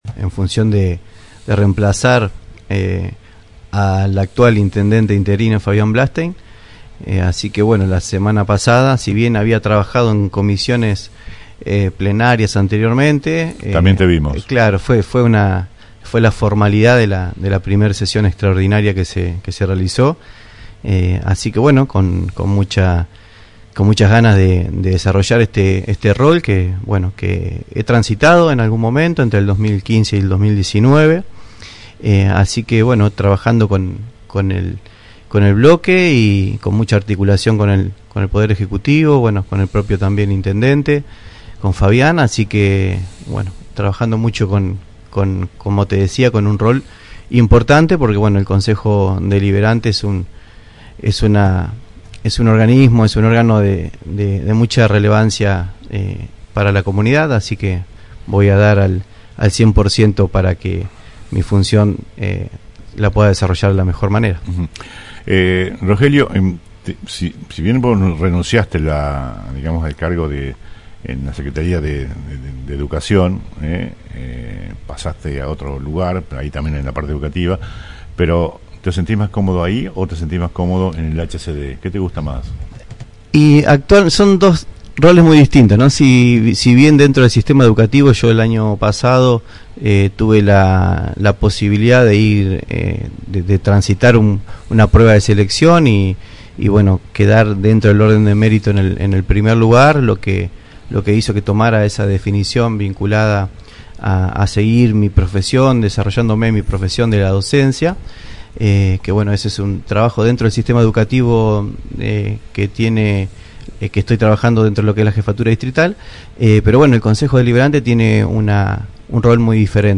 conversó esta mañana en “El Periodístico”